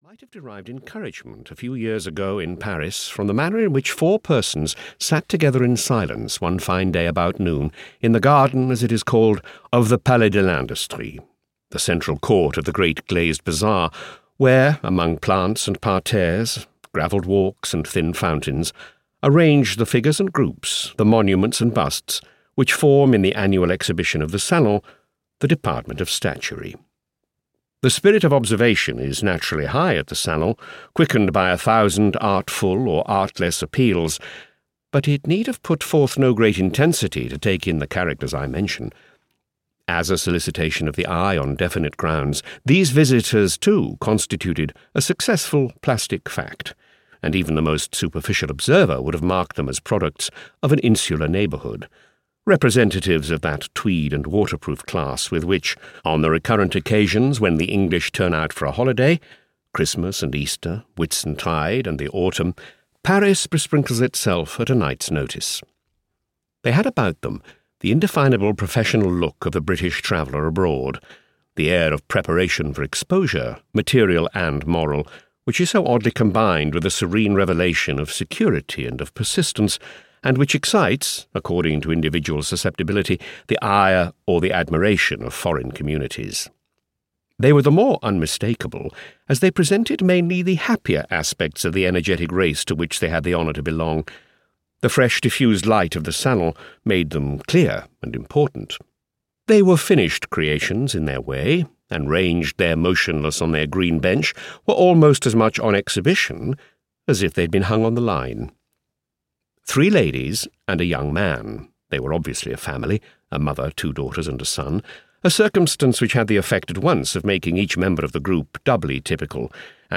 Audio knihaThe Tragic Muse (EN)
Ukázka z knihy